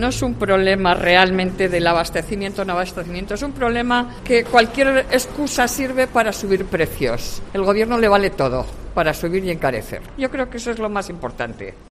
Clienta Mercado San Blas de Logroño